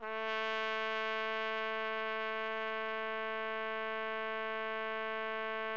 TRUMPET    4.wav